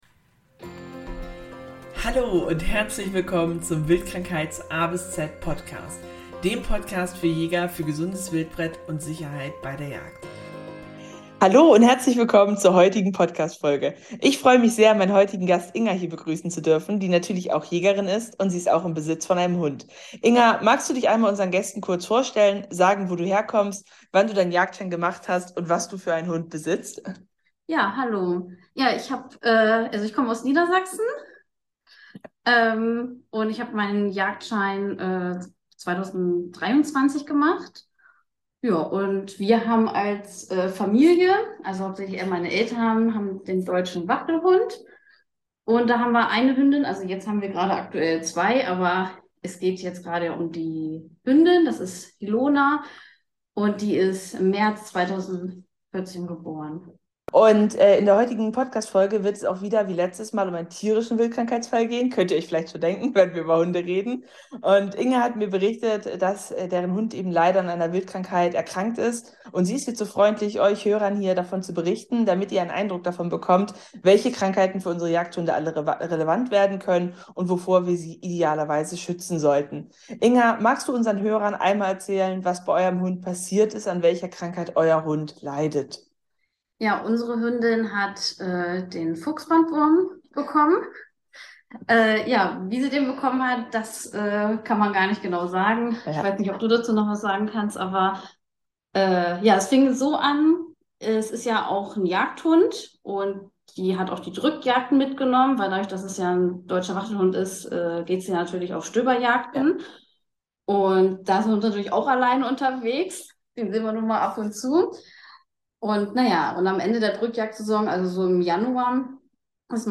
Beschreibung vor 8 Monaten Folge 6: Zwischenwirt Hund - ein Jagdhund mit Fuchsbandwurmfinnen In dieser Folge spreche ich mit einer Jägerin, deren Hund von Fuchsbandwurmfinnen betroffen ist.